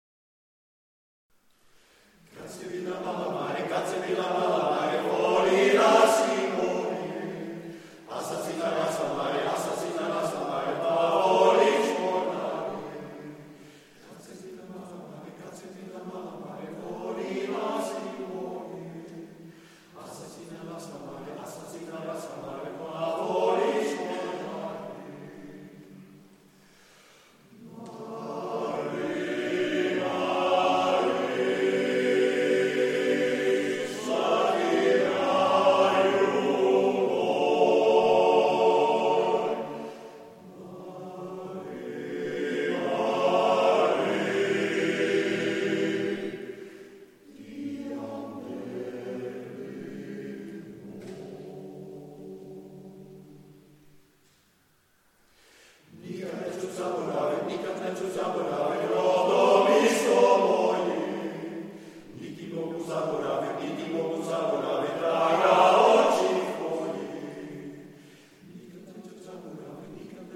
Muziek concert 2009 en missen: